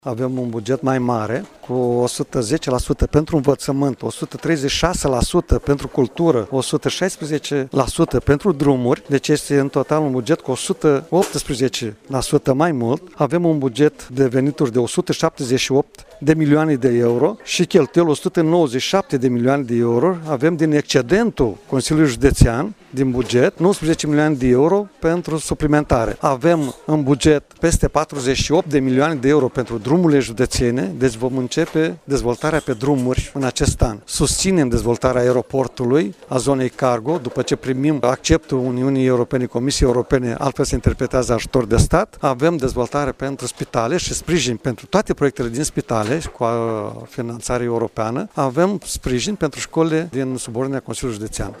Președintele Consiliului Județean Iași, Maricel Popa, a precizat că veniturile județului se ridică la 178 de milioane de Euro, mai mult cu 16 la sută decât anul precedent.